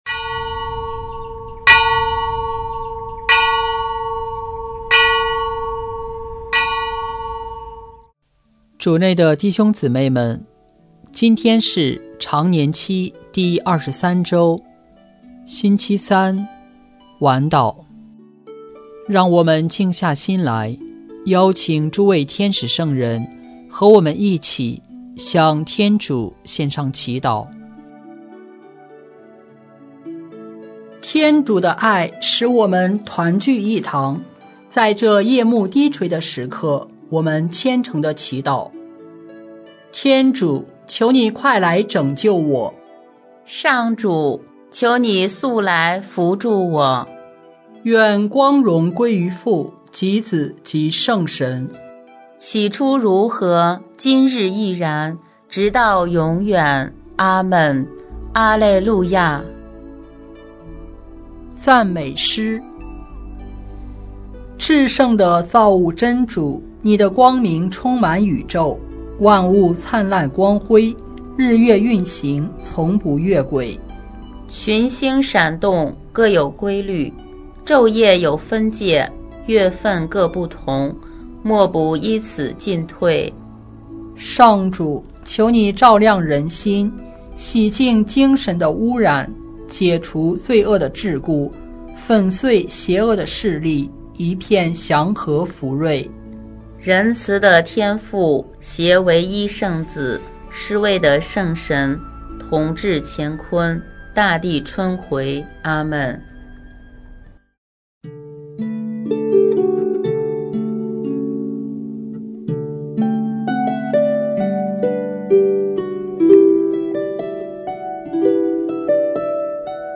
圣咏吟唱 圣咏 125 我们的喜乐和希望全在天主 “你们如何分担了痛苦，也要同样共享安慰。”